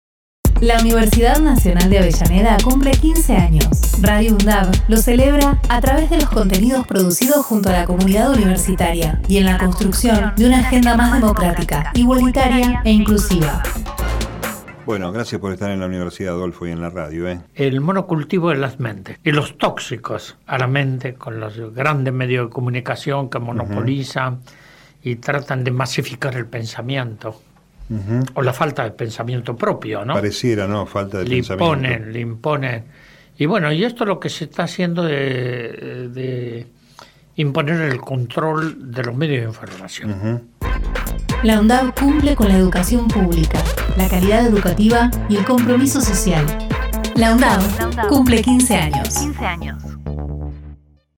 Adolfo Pérez Esquivel (Archivo Radio UNDAV - 2016)
Spot LaUndavCumple_Esquivel.mp3